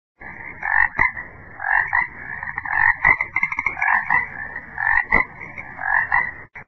Frog sms ringtones dowload ringtone free download
Animals sounds